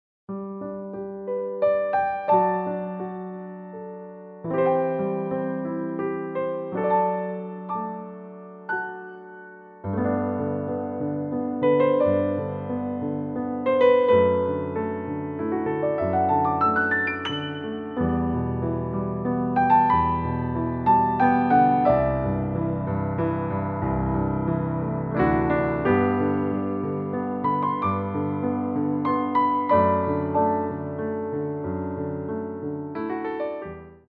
3/4 (8x8)